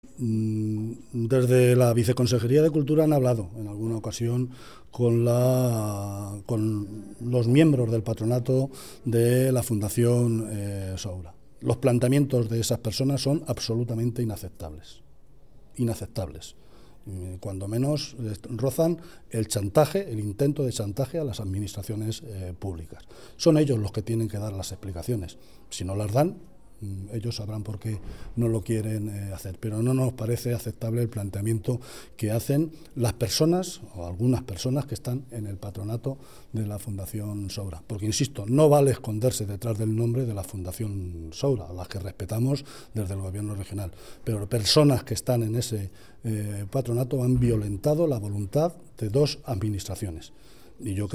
Cuenca, Local Así lo ha asegurado esta mañana en Cuenca, preguntado por los medios de comunicación.
Declaraciones de José Luis Martínez Guijarro: